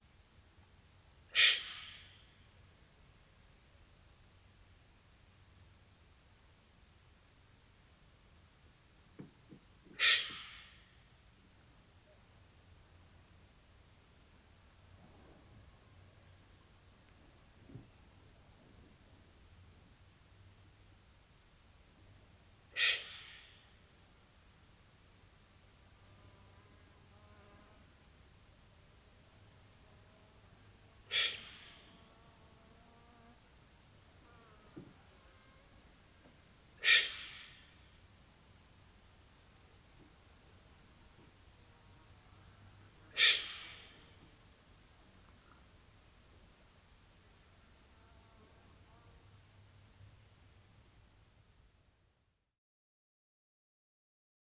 Pharaoh Eagle-owl
25-Pharaoh-Eagle-Owl-Begging-Calls-Of-Juvenile.wav